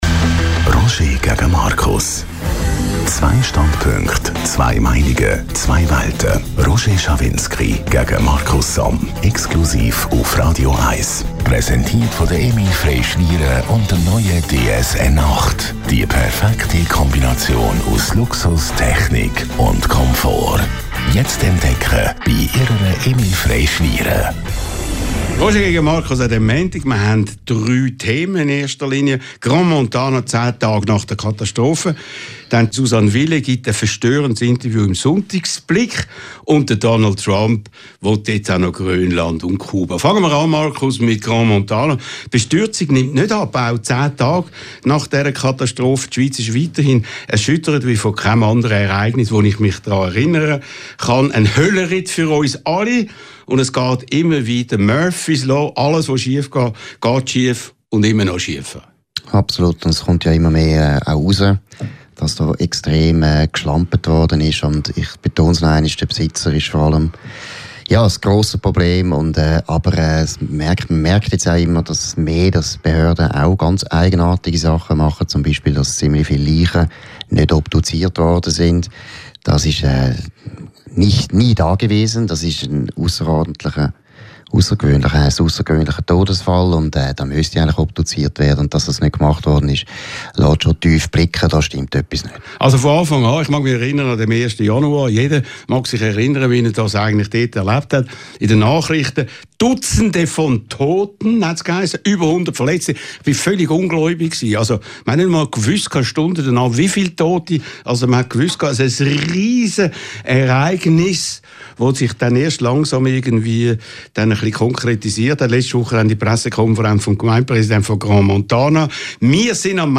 Publizist Markus Somm und Radio 1-Chef Roger Schawinski diskutieren in kontroverser Form über aktuelle Themen der Woche.